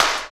108 CLP SN-R.wav